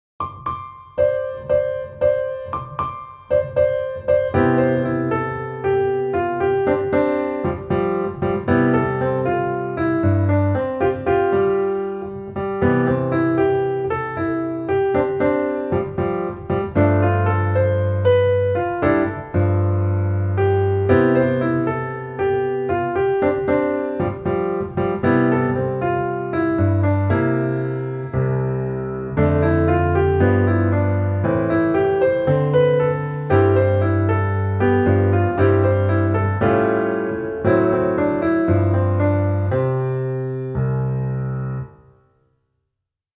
for Trolley Scene (Piano Only)